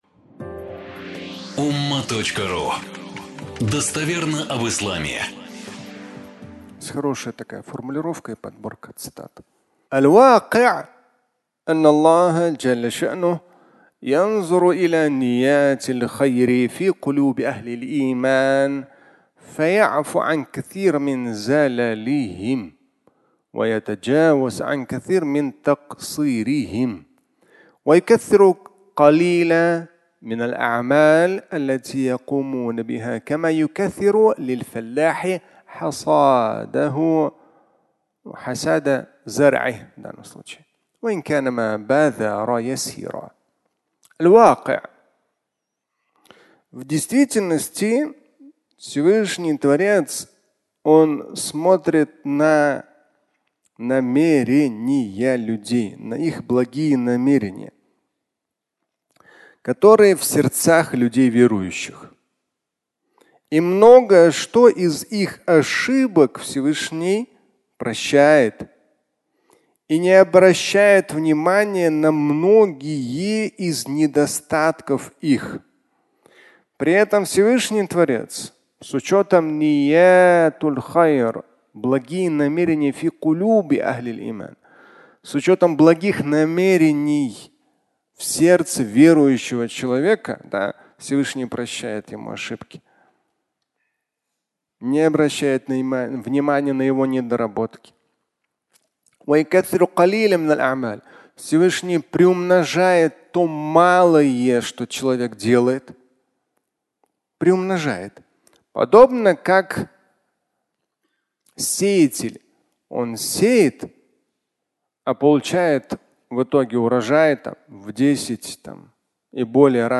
Рай близок (аудиолекция)